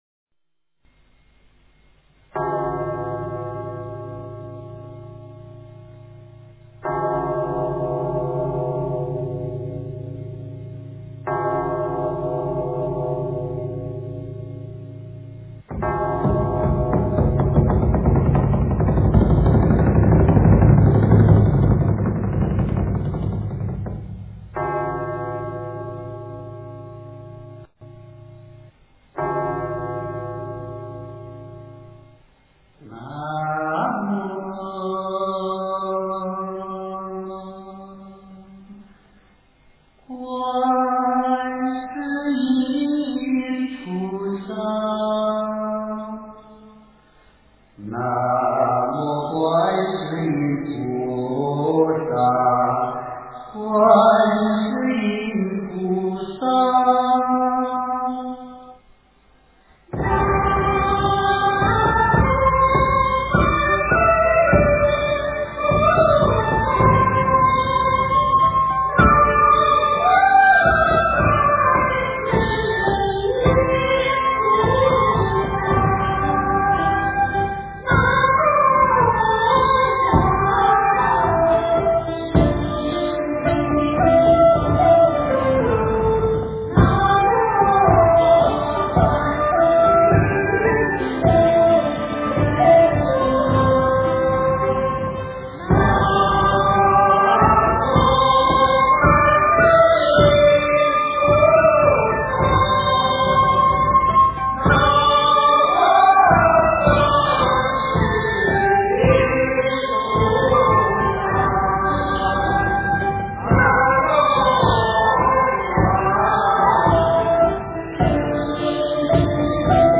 经忏
佛音 经忏 佛教音乐 返回列表 上一篇： 佛说阿弥陀佛经--中国佛学院法师 下一篇： 南无阿弥陀佛--华乐伴奏 相关文章 弥陀圣号-七音调--佛光山梵呗团 弥陀圣号-七音调--佛光山梵呗团...